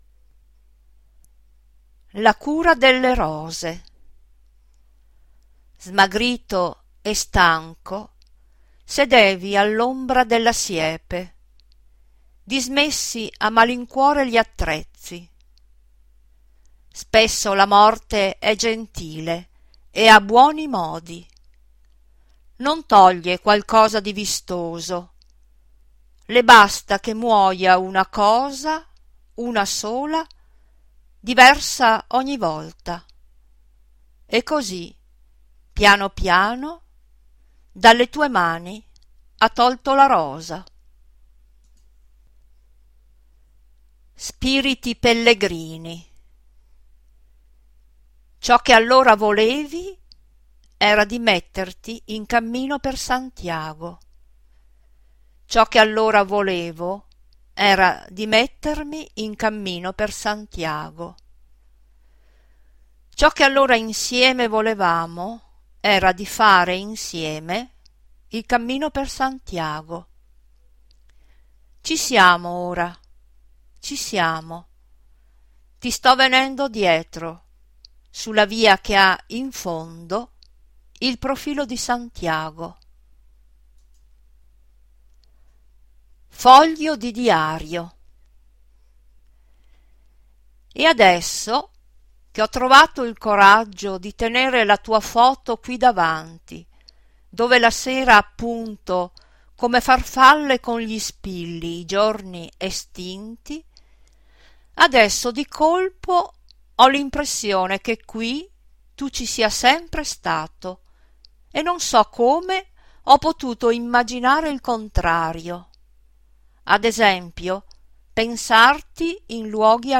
legge "La cura delle rose" , dalla raccolta "La memoria non ha palpebre"